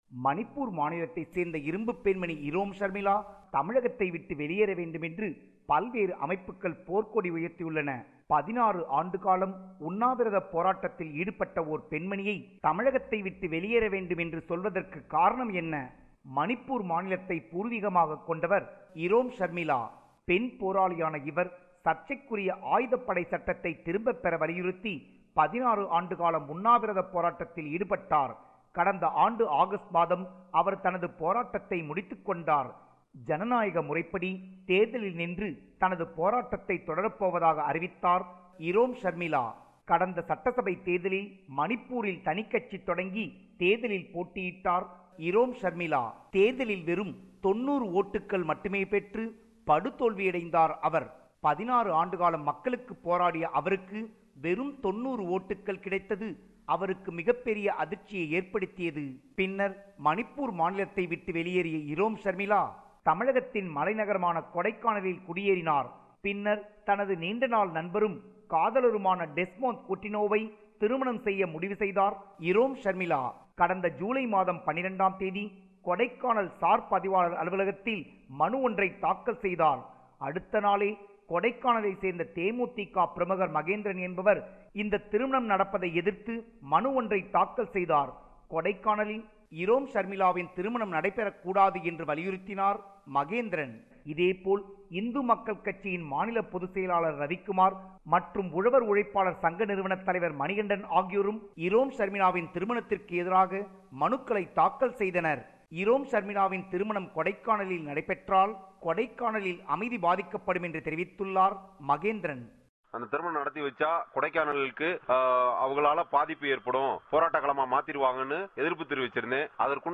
Tamil News